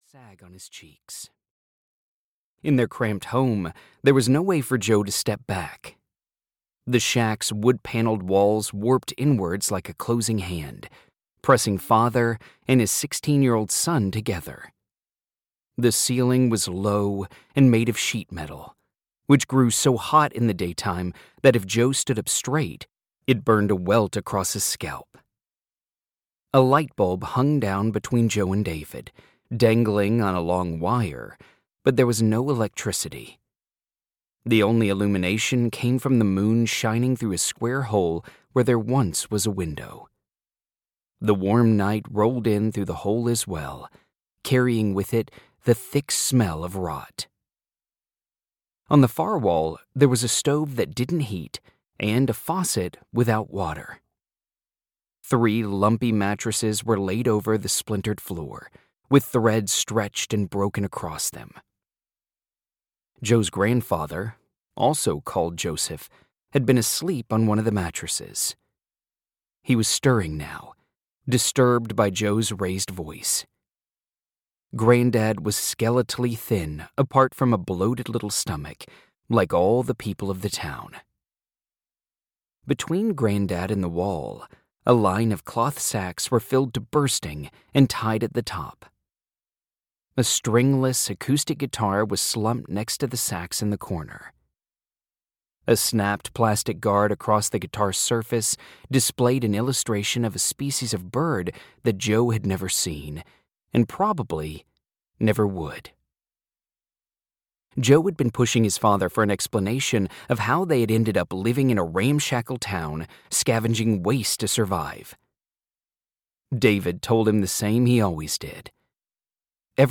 Landfill Mountains (EN) audiokniha
Ukázka z knihy